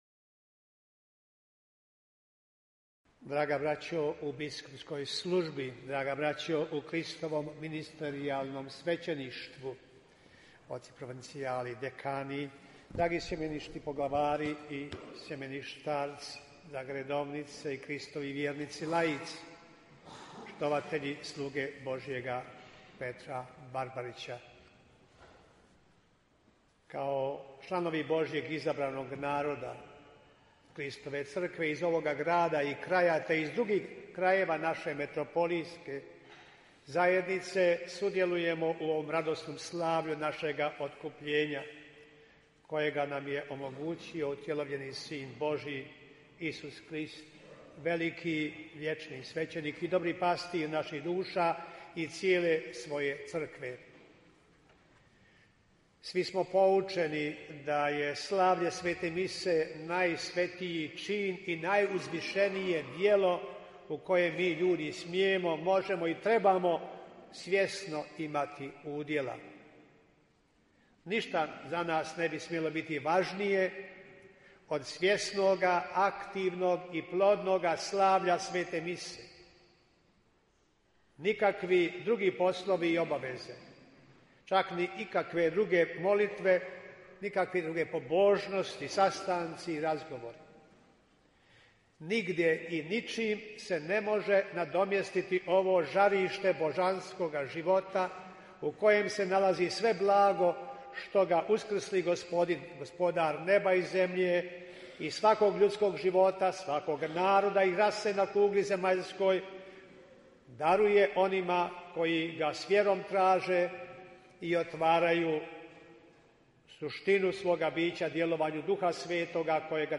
AUDIO: PROPOVIJED BISKUPA KOMARICE NA VI. MEĐUDEKANSKOM SUSRETU U BIH